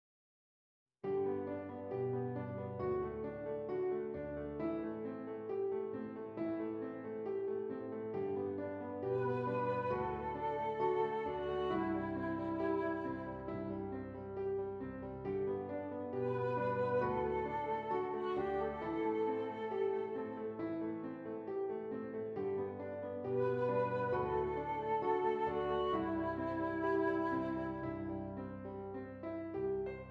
Flute Solo with Piano Accompaniment
G Major
Slow